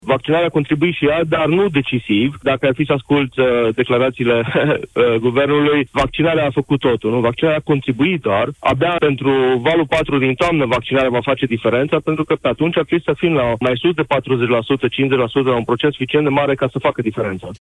Invitat în emisiunea Deşteptarea la Europa FM, el a adăugat că la scăderea incidenţei au contribuit restricţiile impuse de autorităţi şi vremea caldă: